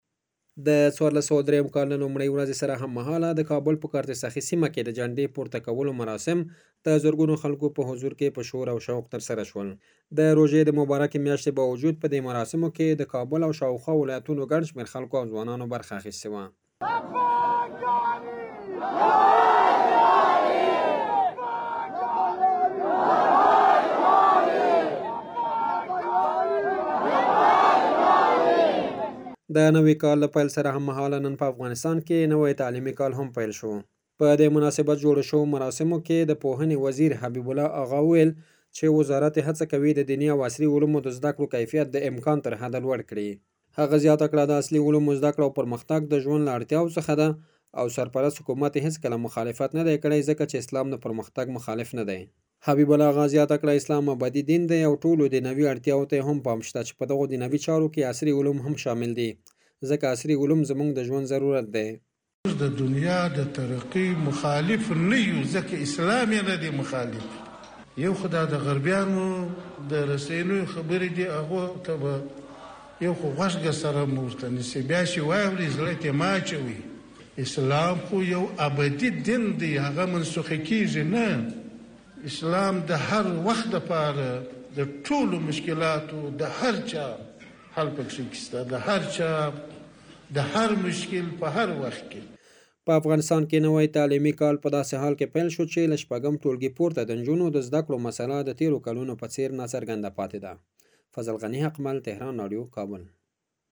ایکنانیوز د پارس ټو ډې له قوله راپور راکړ،د ۱۴۰۳ لمریز کال له لومړۍ ورځې سره هم مهاله او د نوروز د نیکمرغه اختر په لومړۍ ورځ د کابل په کارته سخي زیارت کې د جنډې پورته کولو دستورې د زرګونو خلکو په شتون کې په  په ولولې او شوق سره ترسره شول.